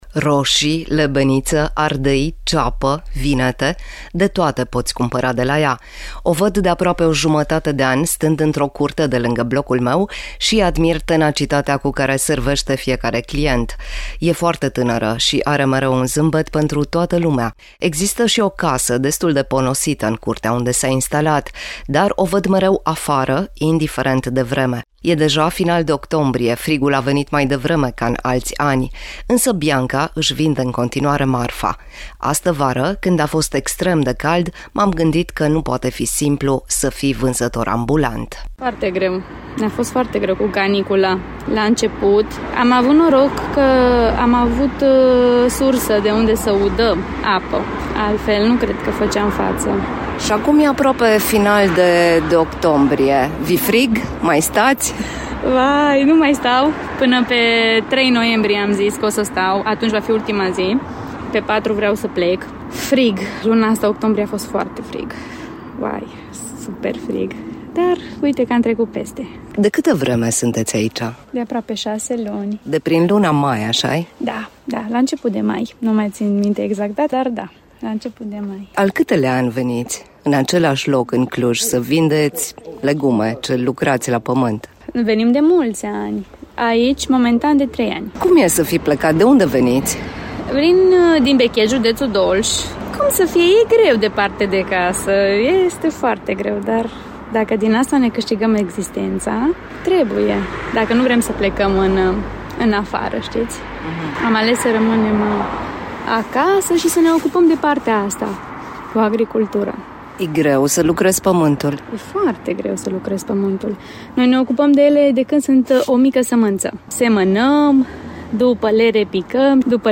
Prima pagină » Reportaje » Cine-a pus aprozaru’-n drum?
Așa că profit de soarele timid din ultimele zile și mă opresc să stau de vorbă cu ea.
La început se uită destul de sceptică la telefonul cu care înregistrez, dar devine destul de repede surprinzător de dezinvoltă.